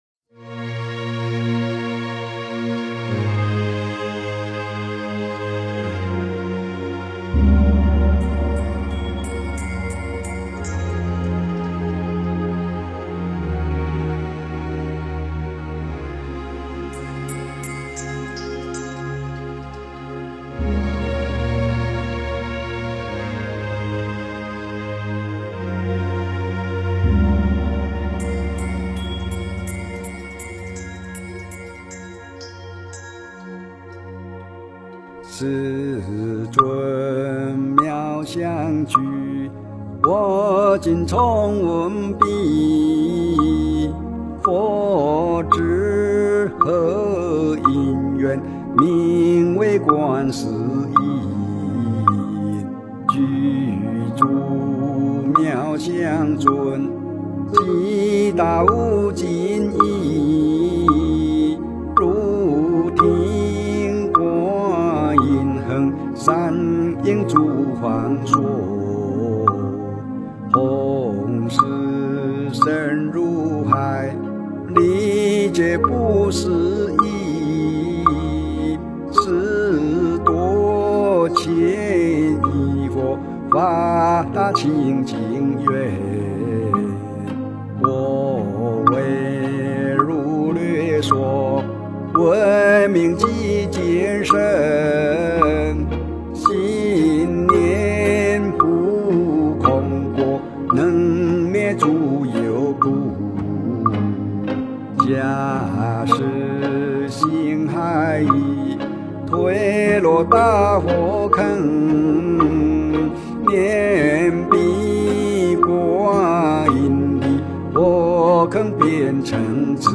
诵经
佛音 诵经 佛教音乐 返回列表 上一篇： 六字大明咒 下一篇： 三皈依 相关文章 05.